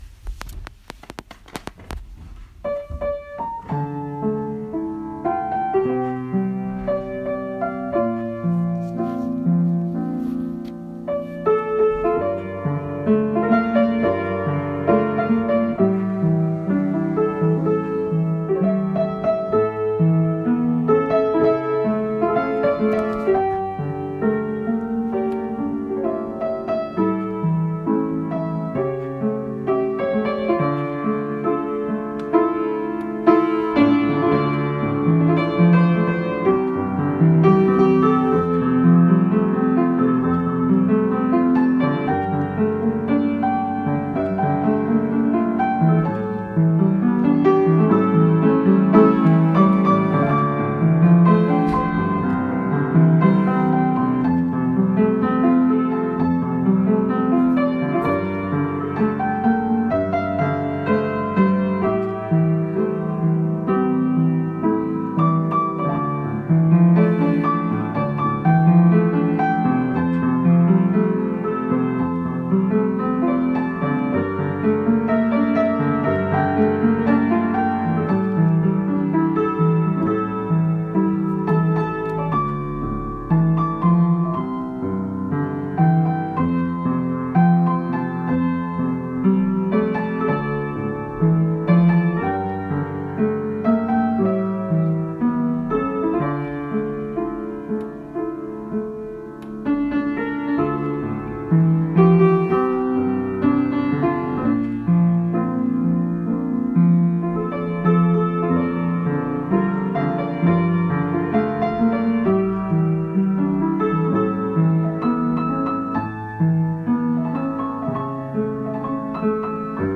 Melody only